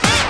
SWINGSTAB 14.wav